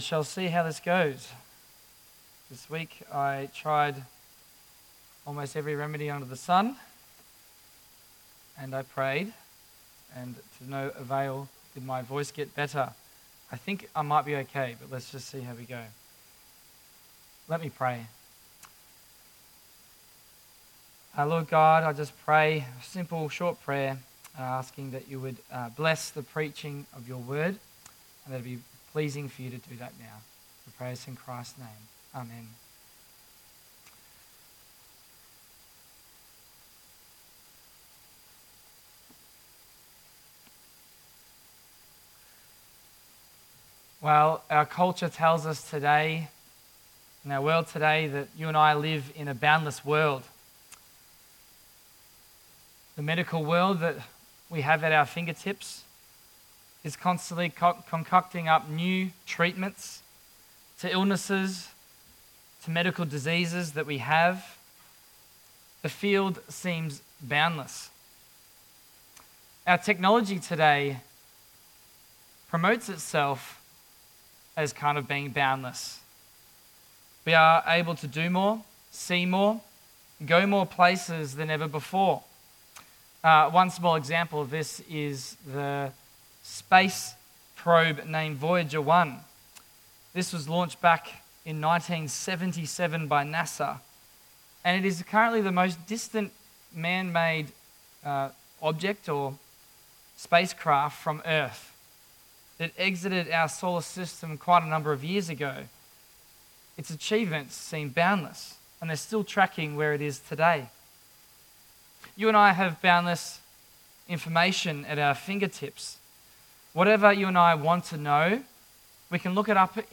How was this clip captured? Passage: Matthew 15:21-39 Service Type: AM